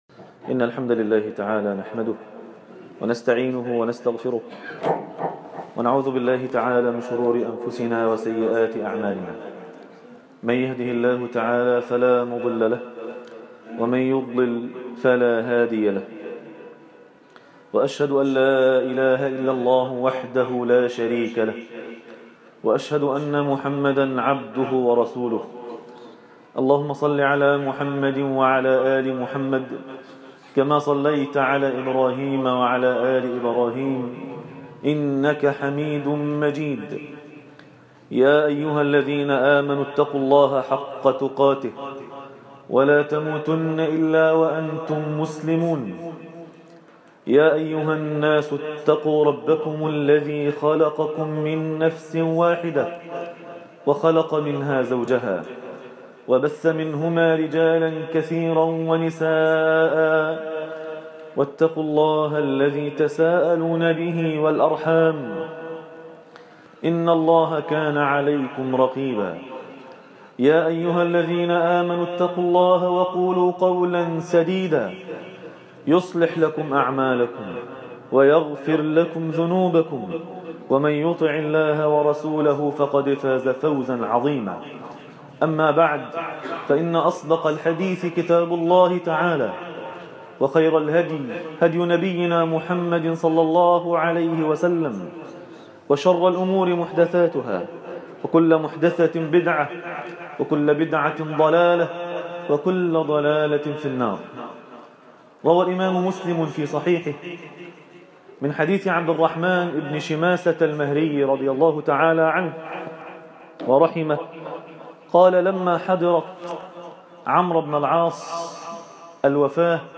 الله الحليم | خطبة جمعة